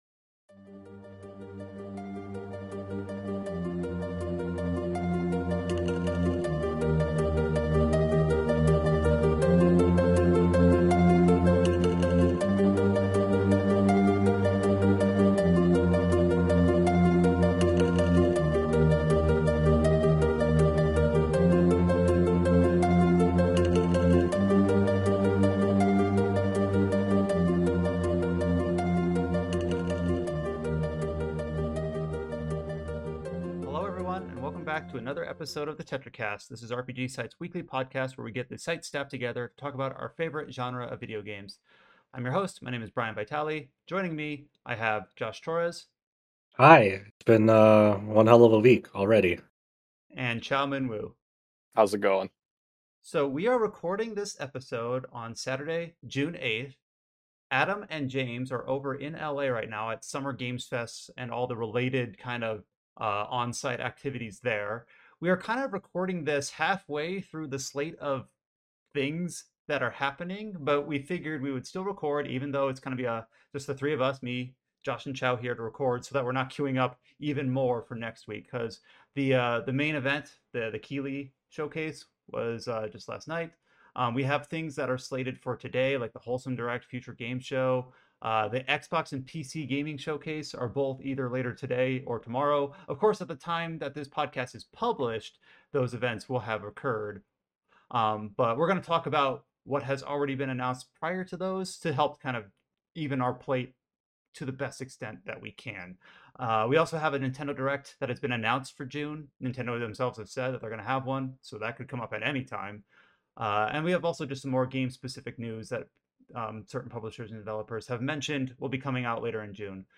In this news-focused episode